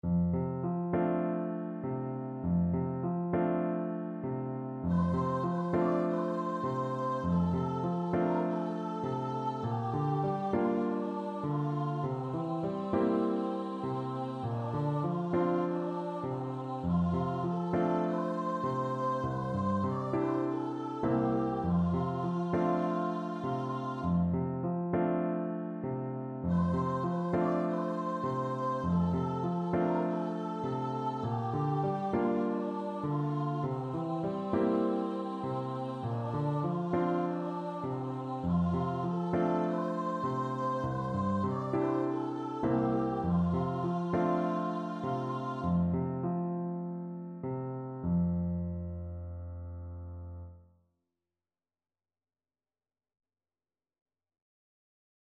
Traditional Music of unknown author.
Gently
4/4 (View more 4/4 Music)
C5-D6